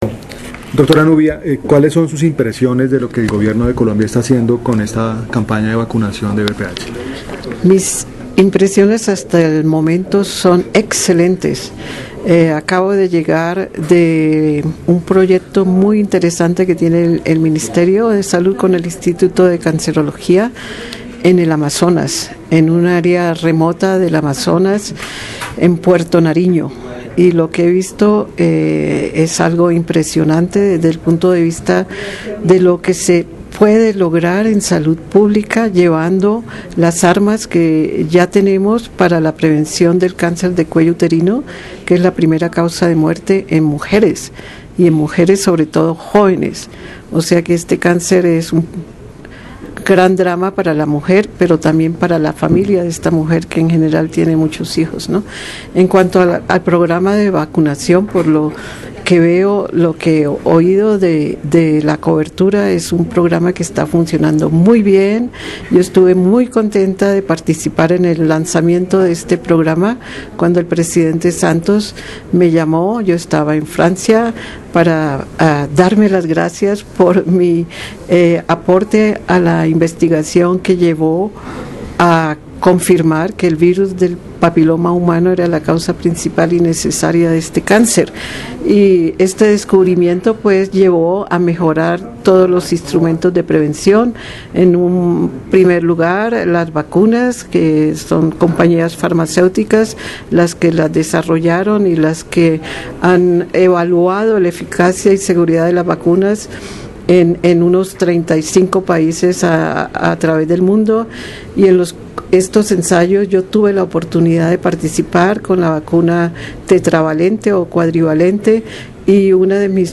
Nubia Muñoz habla de vacuna VPH.wma